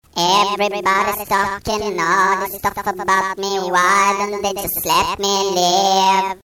3. SUONERIE TRUE TONES